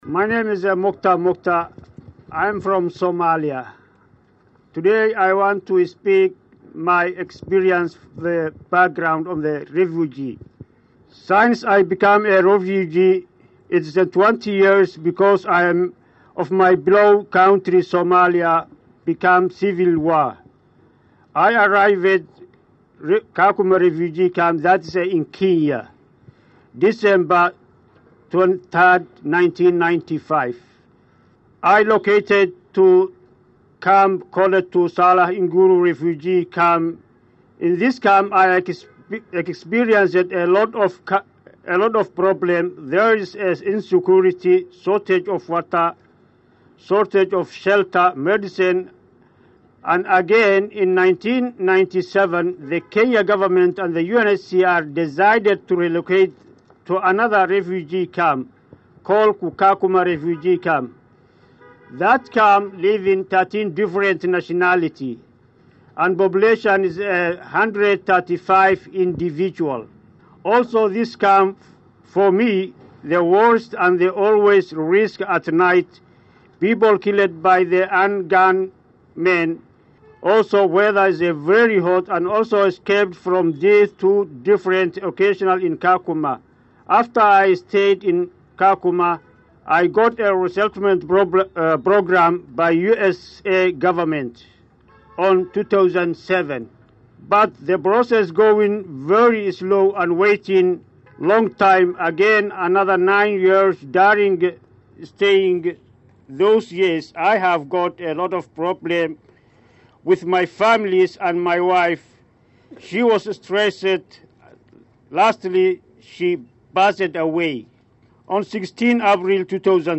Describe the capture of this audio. Demonstrations began locally on Friday morning, as about 150 people gathered in downtown Durham for a rally organized by Church World Service.